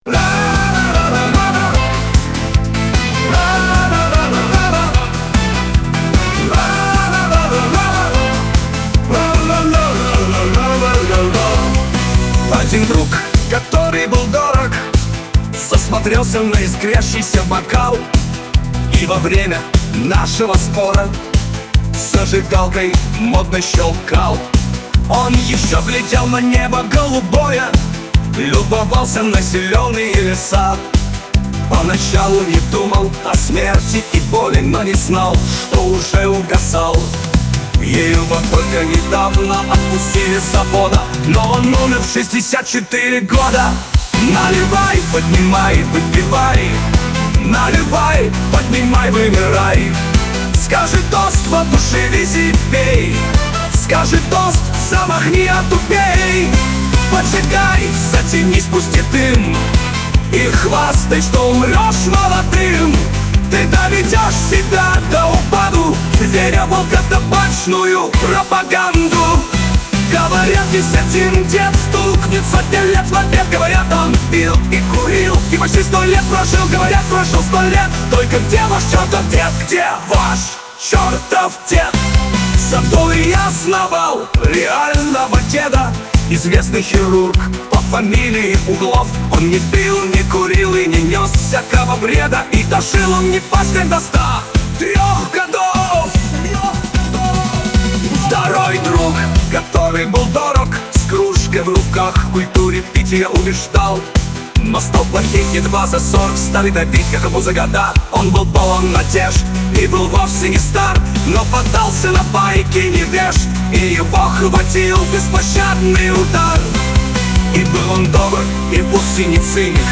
Поучительная песня «Один дед пил и курил – где этот дед?»
Музыка и исполнение – ИИ (во, дожили!).